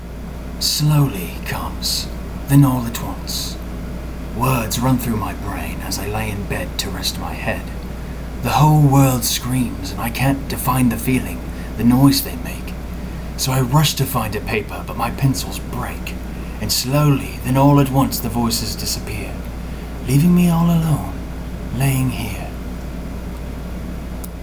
Inspiration [Audio Poem]